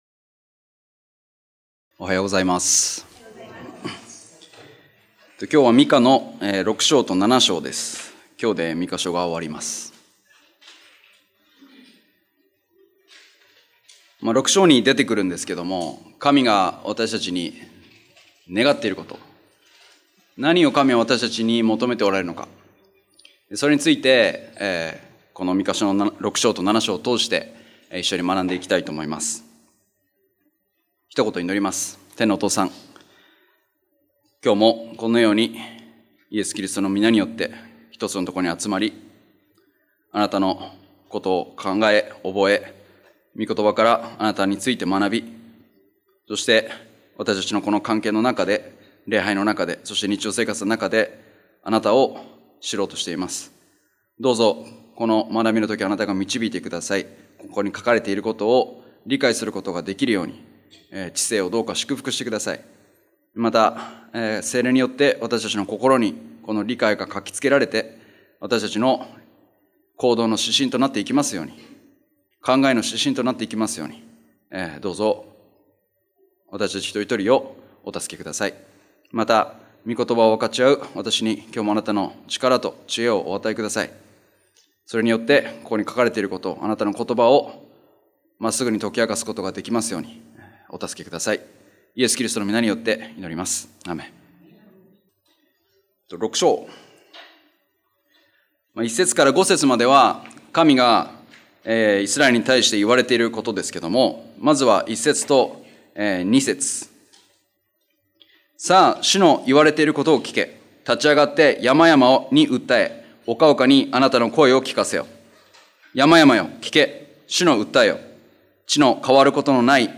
日曜礼拝：ミカ書
礼拝メッセージ